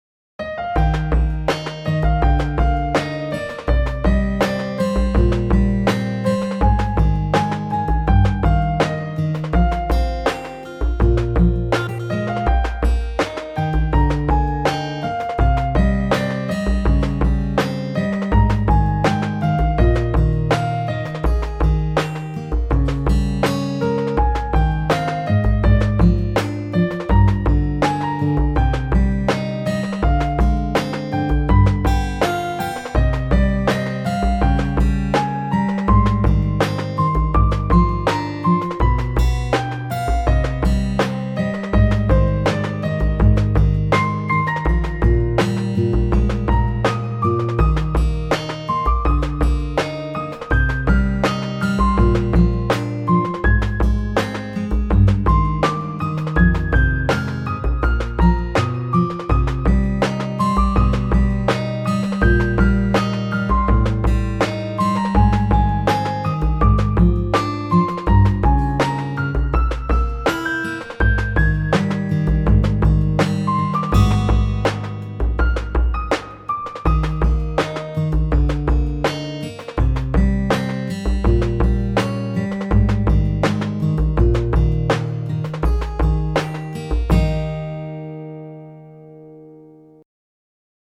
セルビナがくれたものピアノ-1.mp3